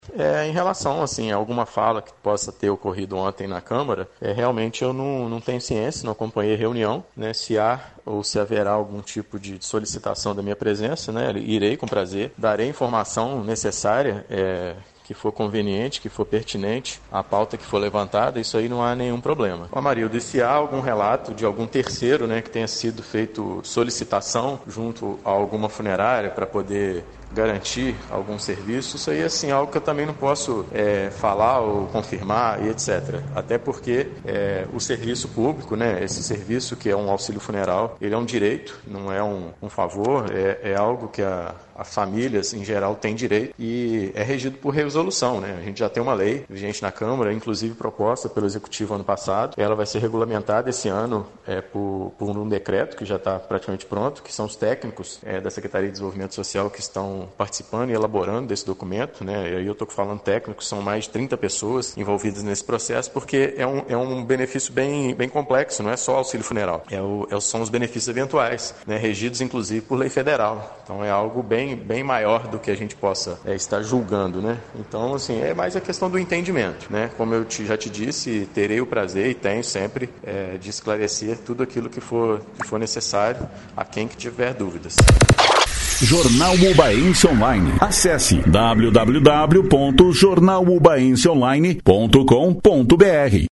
Em contato via aplicativo de mensagens, nossa redação recebeu, em áudio, uma explicação sucinta por parte do secretário municipal de desenvolvimento social, Flávio Monteze.